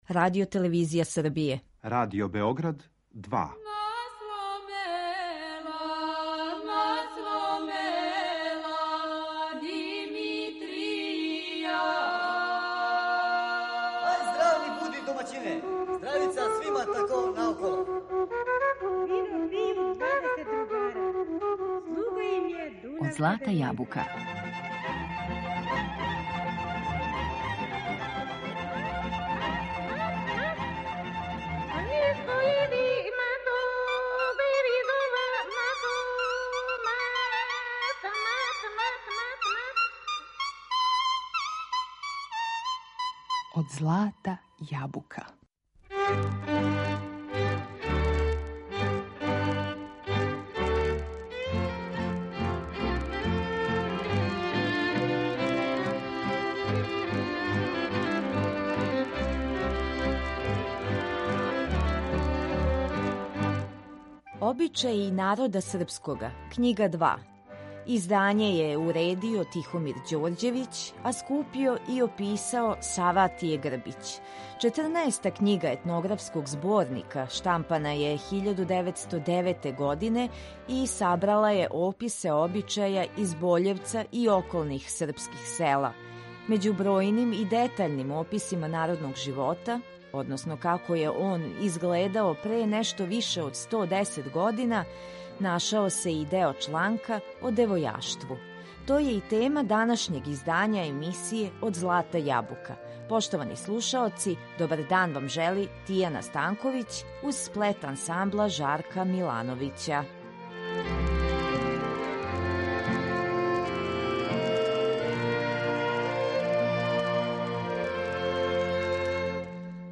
Међу бројним и детаљним описима народног живота, како је изгледао пре нешто више од сто десет година, нашао се и чланaк о девојаштву. Најинтересантније детаље овог текста илустроваће песме вокалних солиста Радио Београда.